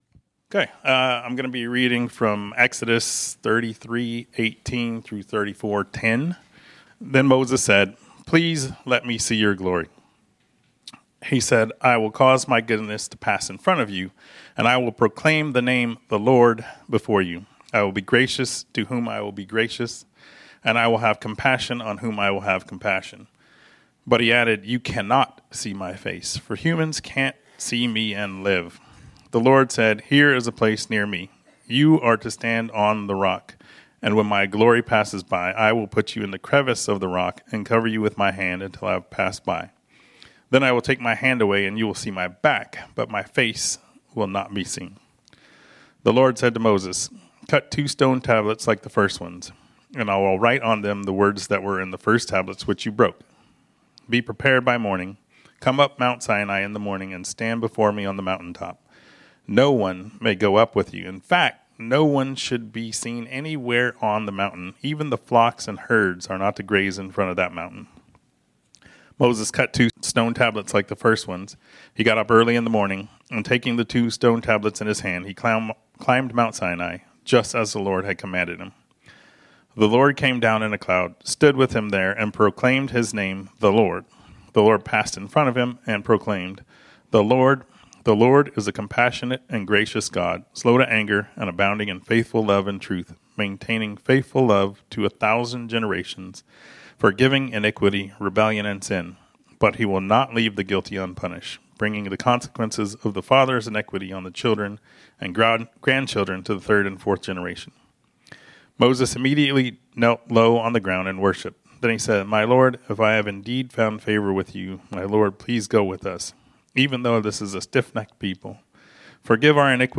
This sermon was originally preached on Sunday, May 18, 2025.